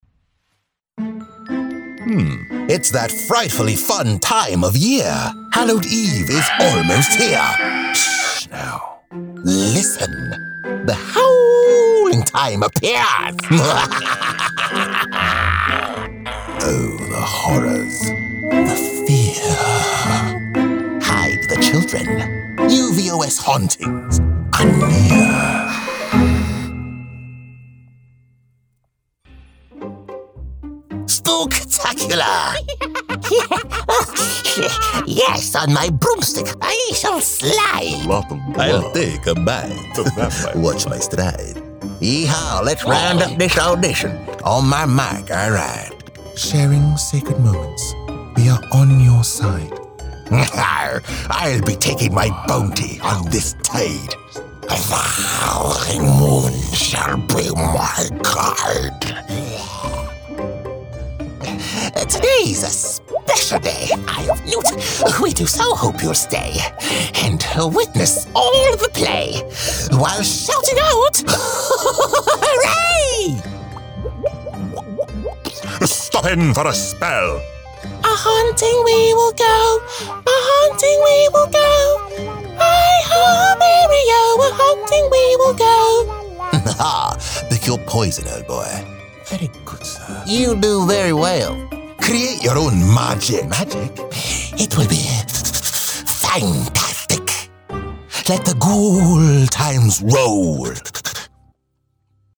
Profundo, Natural, Llamativo, Travieso, Versátil